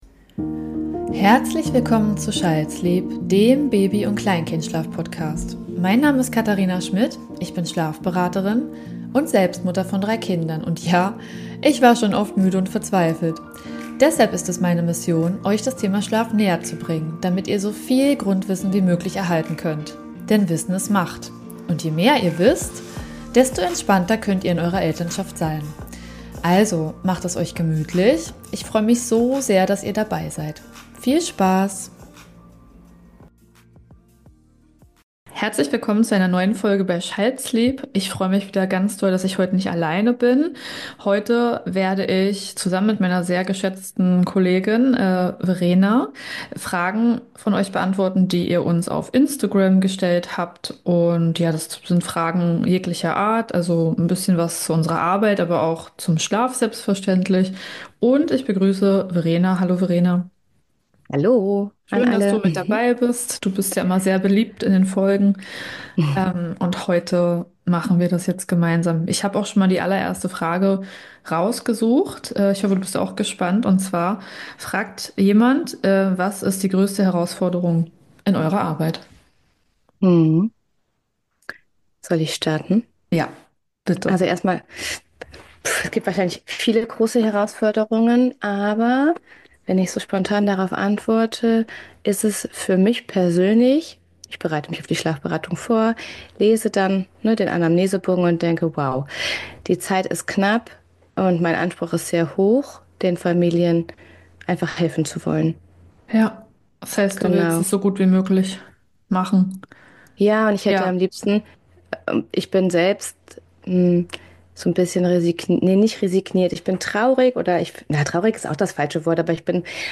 Ganz ehrlich und aus dem Alltag geplaudert.